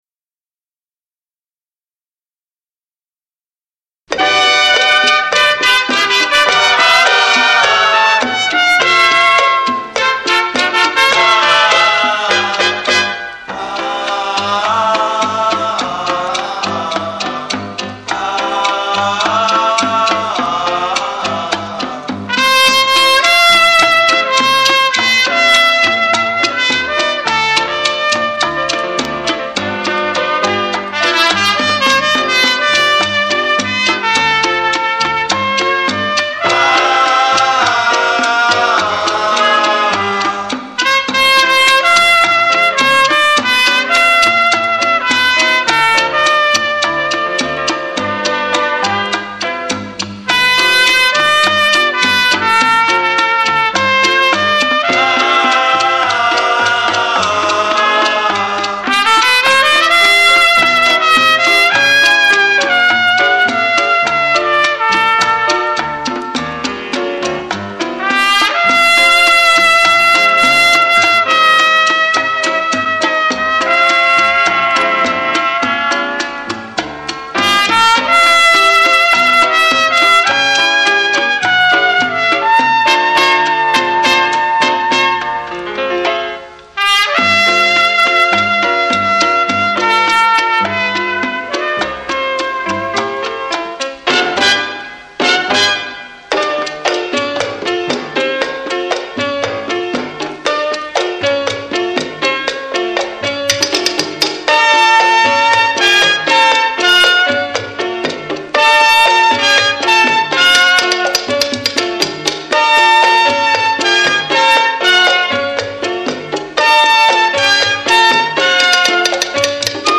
bolero mambo